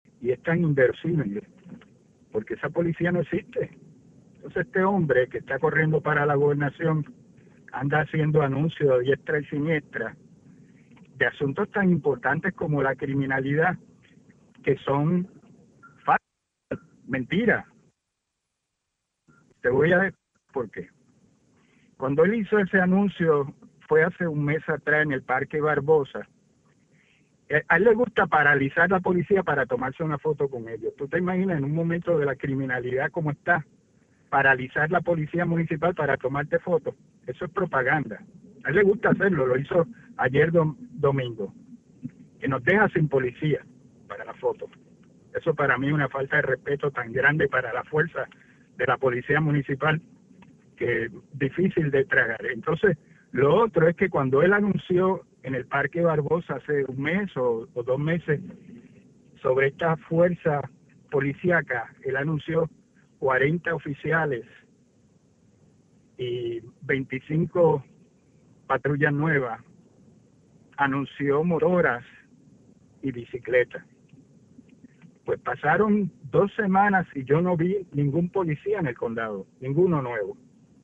en entrevista con Radio Isla